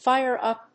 アクセントfíre úp